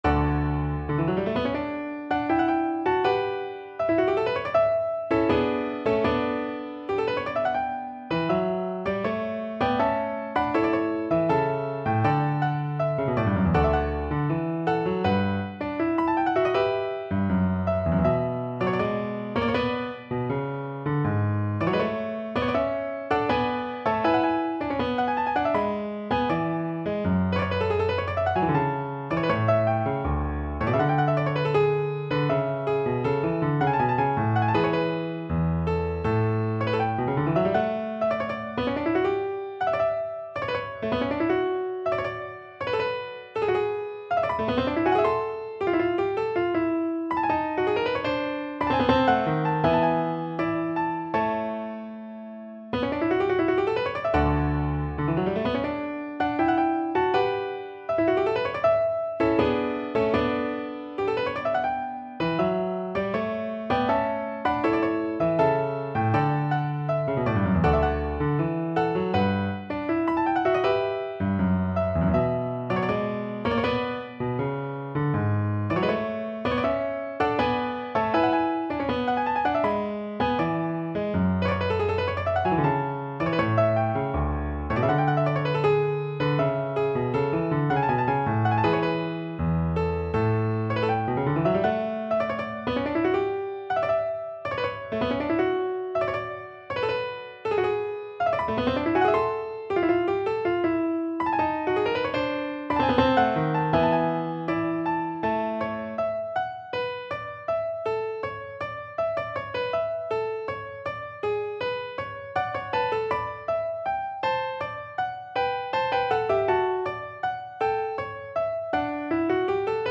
for solo piano
Instrument: Piano
Style: Classical